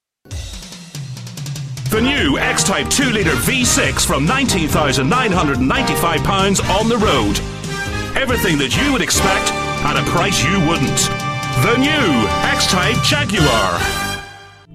Automotive
I have a soft engaging Northern Irish Accent
Baritone , Masculine , Versatile . Commercial to Corporate , Conversational to Announcer . I have a deep, versatile, powerful voice, My voice can be thoughtful , authoritative , storytelling and funny . Confident and able to deliver with energy , humour and conviction .
RODE NT1A . TECPORTPRO , ISOLATED SOUND TREATED BOOTH. EDIT WITH AUDACITY